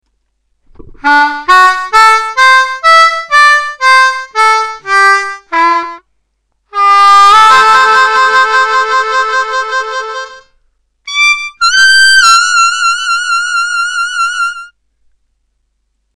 Funkin’ it up on the blues harmonica